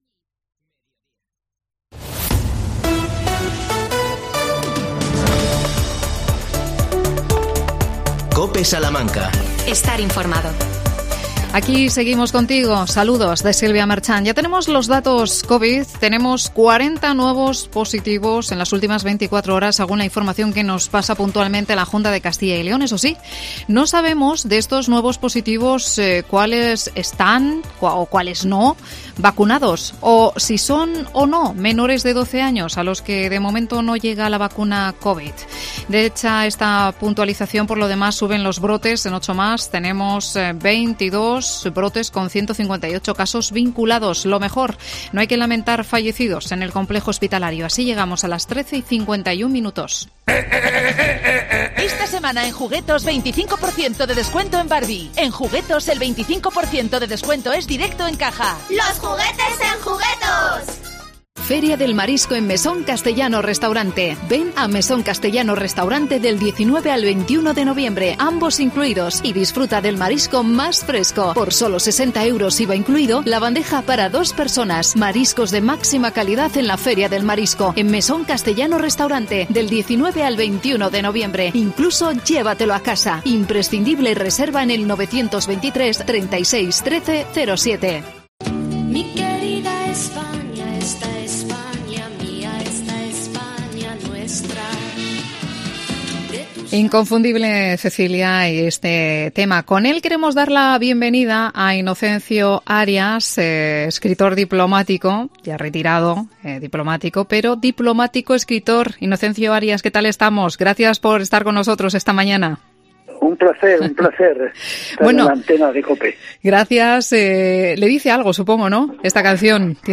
AUDIO: Entrevista a Inocencio Arias que presenta hoy en Salamanca su libro "Esta España Nuestra". Microespacio de la Diputación de Salamanca.